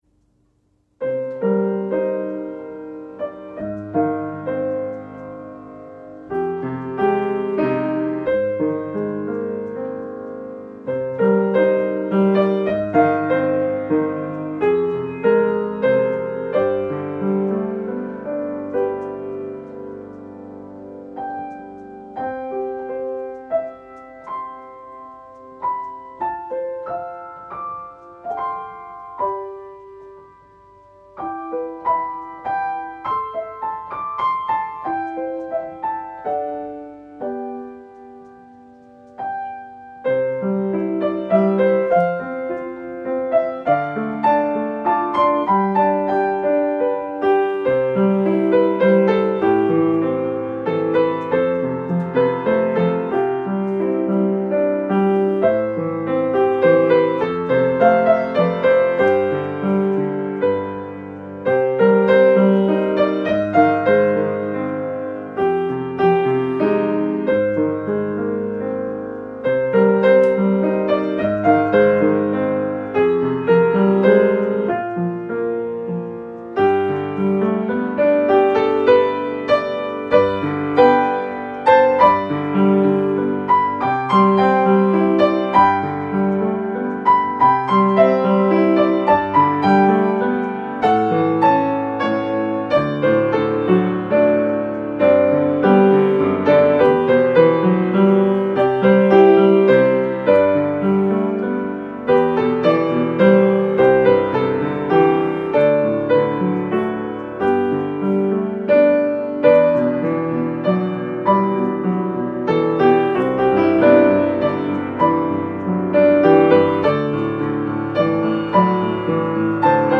Piano Solo
This arrangement is meant to show the progression from darkness to light as we accept the Savior into our lives.